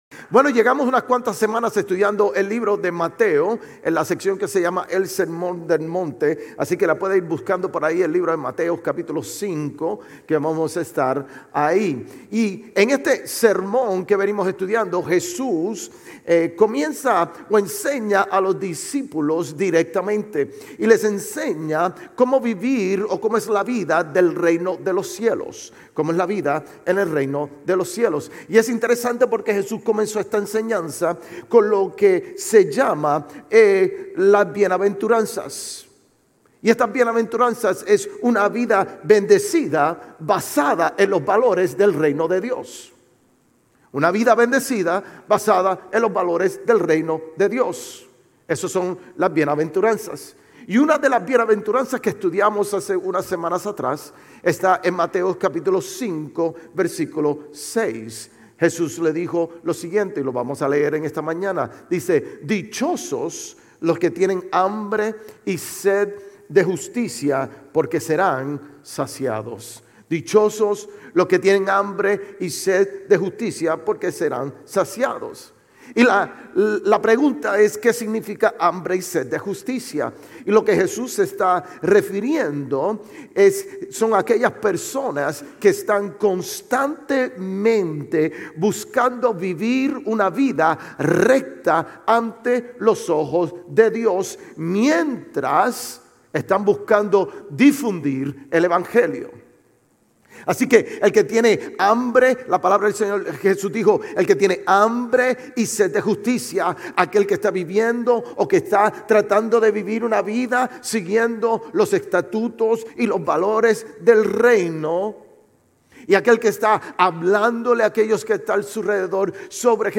Sermones Grace Español 2_9 Grace Español Campus Feb 09 2025 | 00:33:57 1x 00:00 / 00:33:57 Subscribe Share RSS Feed Share Link Embed